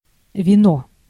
Ääntäminen
Ääntäminen Tuntematon aksentti: IPA: /viːn/ Haettu sana löytyi näillä lähdekielillä: ruotsi Käännös Ääninäyte Substantiivit 1. вино {n} (vino) Muut/tuntemattomat 2. вино́ {n} (vinó) Artikkeli: ett .